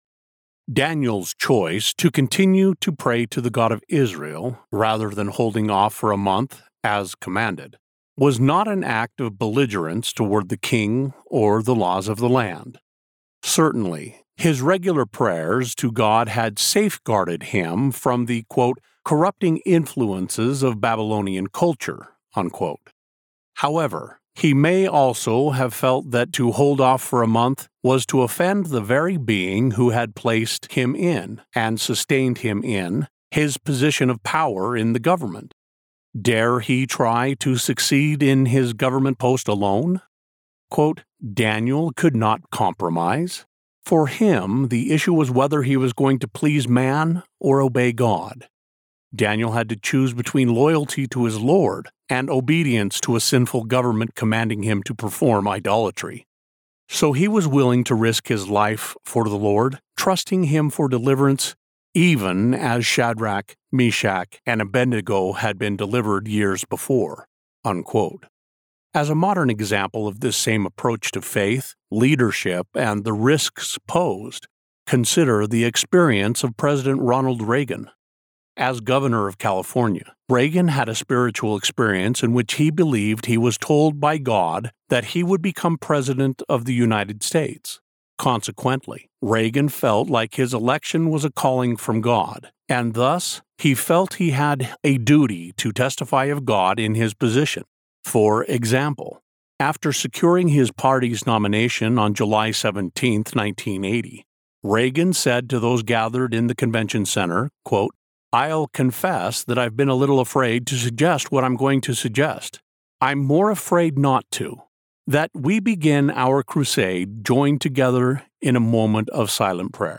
Rich • Robust • Trustworthy
A warm embrace of fatherly wisdom.
Non-Fiction • Religion
Mid-West American